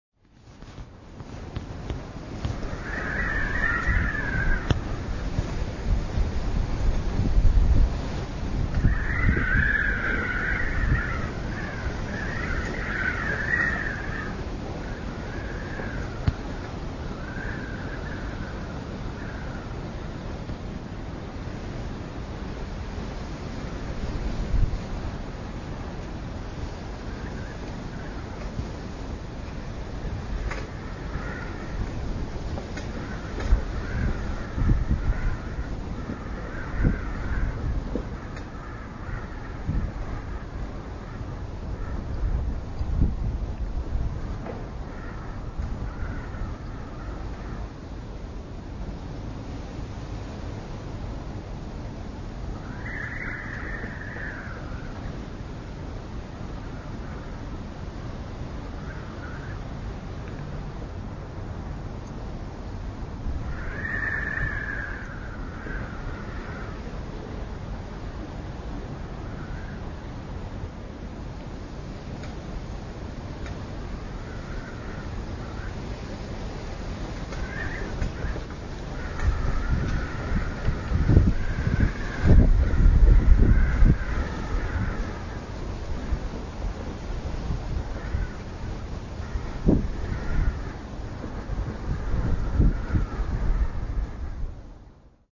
Profiled Aluminium-flagpole; slit singing in the wind (ca. 90 sec, 372k)
windeck_fahnenmast.mp3